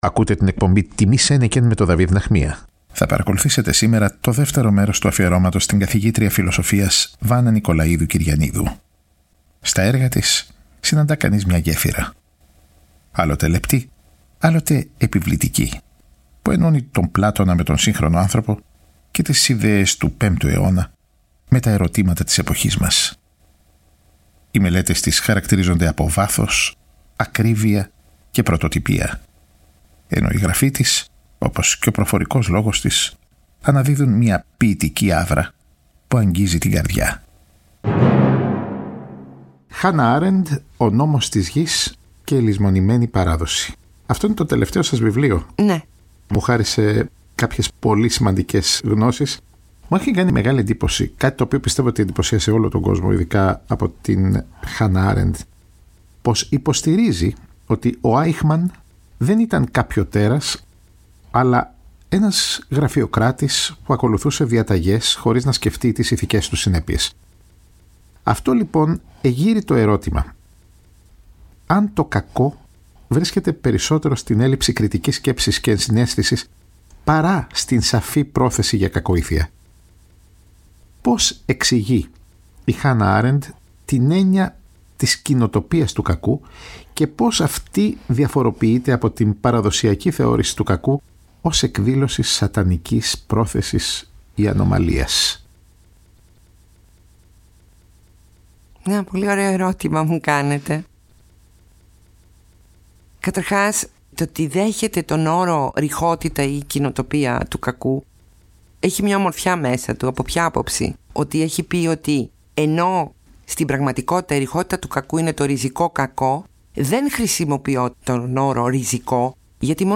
Ακούστε το 2ο μέρος της συνομιλίας τους που μεταδόθηκε το Σάββατο 11 Ιανουαρίου από το Τρίτο Πρόγραμμα.